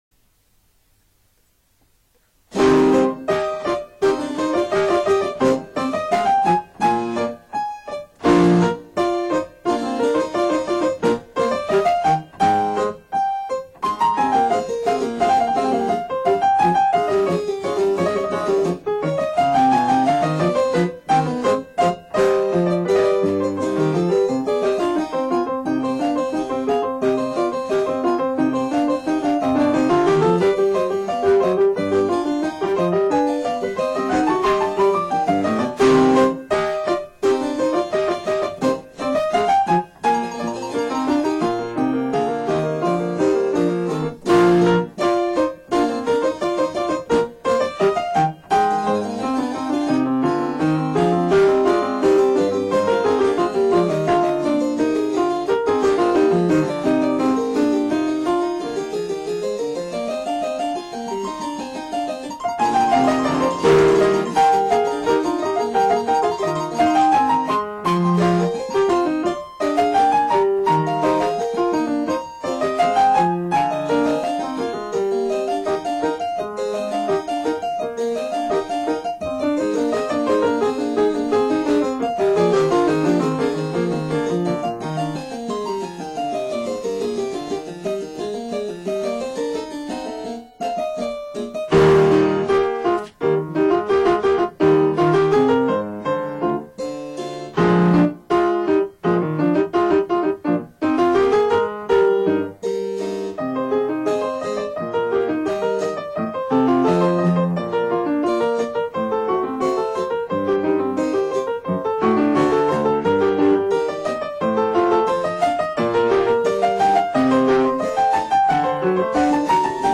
電子ピアノ・チェンバロ
concerto.wma